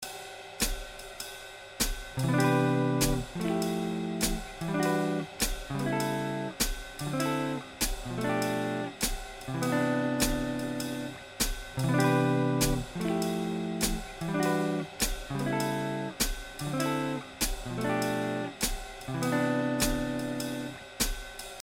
9th Chords in Jazz
To finish off here is a musical example in a jazz style using 9th chords in a typical jazz progression.
9thchord_jazz.mp3